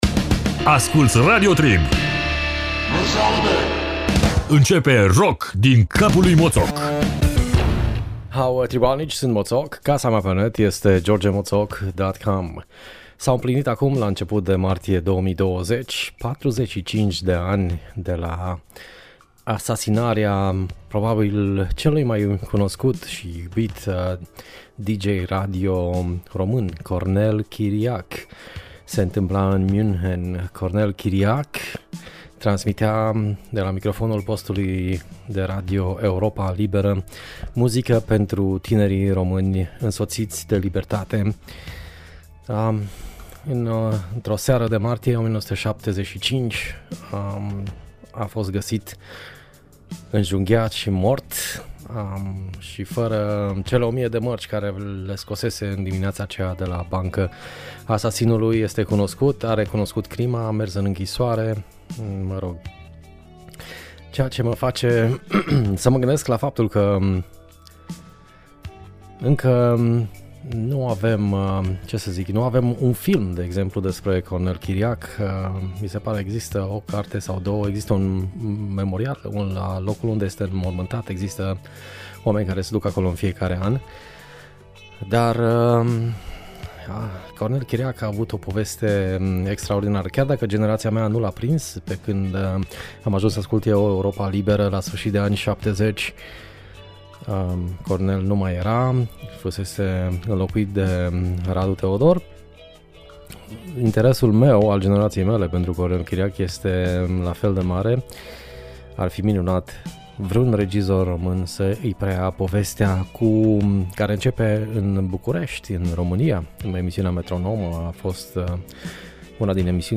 Bluzache cu un mucea nou care tine bine de chitara.